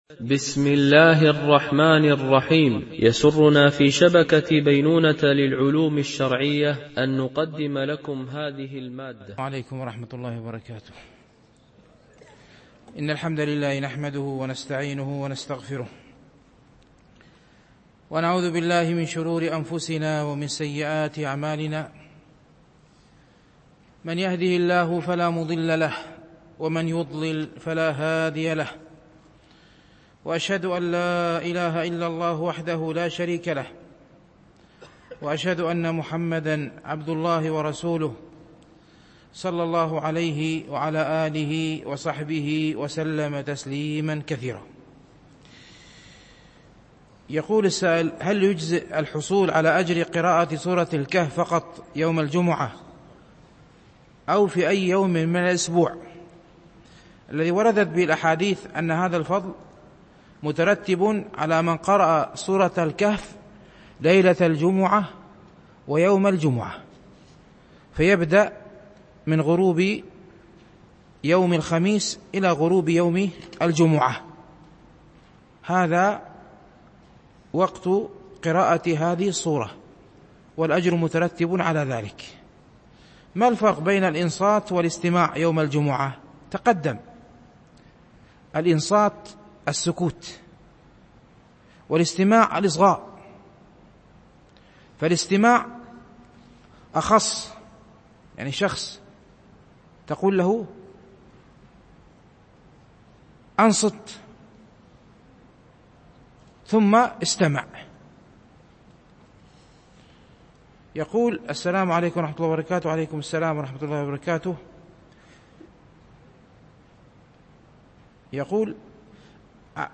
شرح رياض الصالحين – الدرس 297 ( الحديث 1161 )